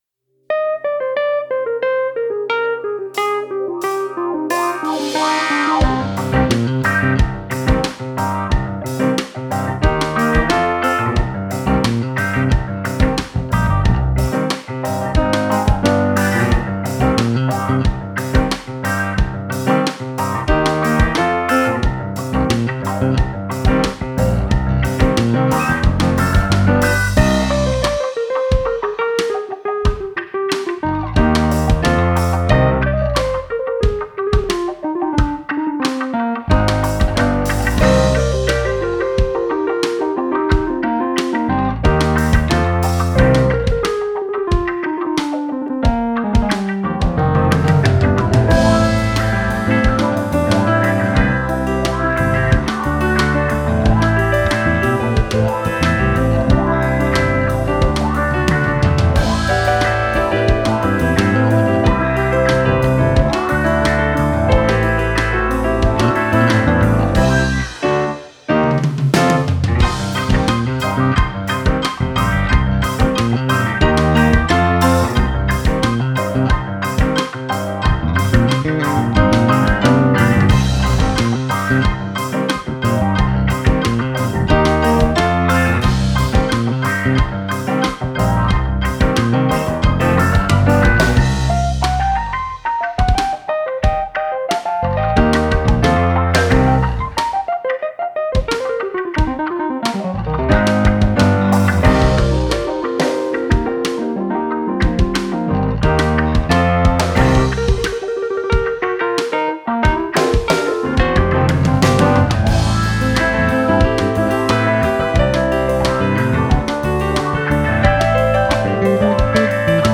Instrumental version: